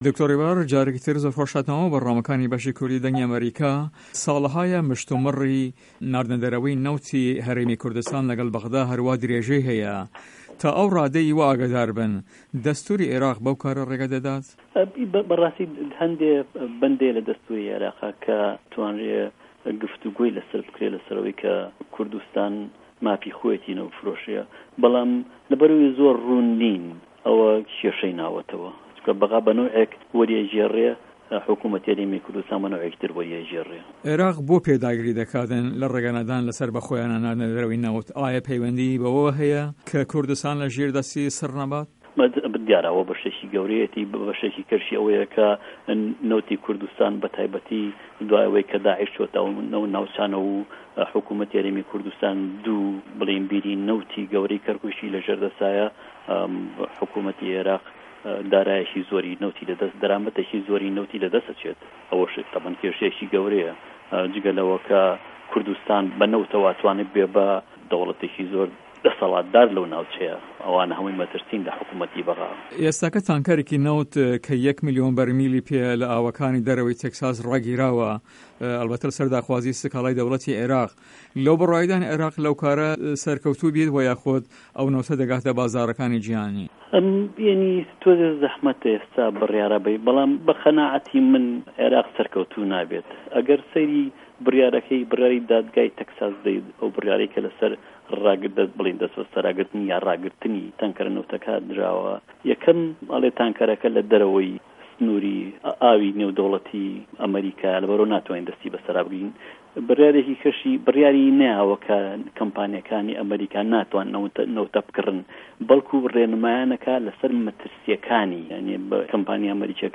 هه‌رێمه‌ کوردیـیه‌کان - گفتوگۆکان
له‌ هه‌ڤپه‌ێڤینێکدا له‌گه‌ڵ ته‌له‌/ رادێۆێ به‌شی کوردی ده‌نگی ئه‌مه‌ریکا